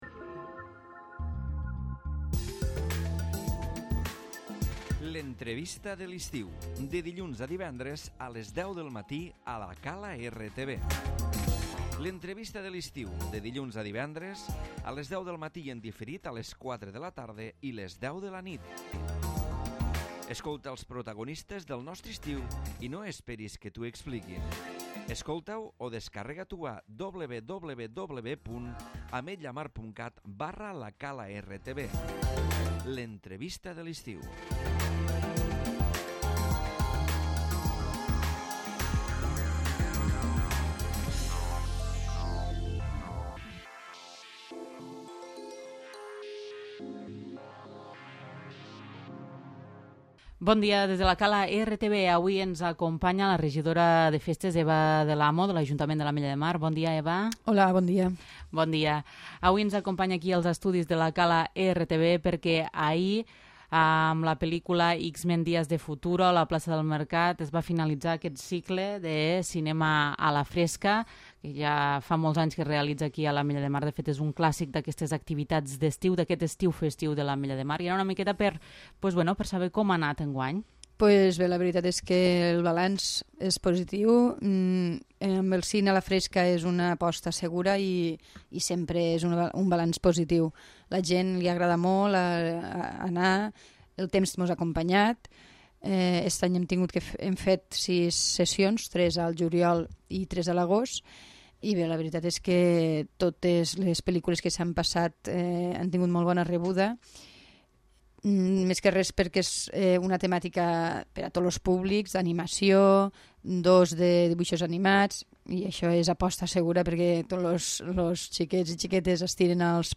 L'Entrevista
Avui ens ha acompanyat Eva del Amo, regidora de Festes, que ens ha parlat del cinema a la fresca i ha fet balanç de l'Estiu Festiu de l'Ametlla de Mar.